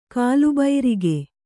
♪ kālu bairige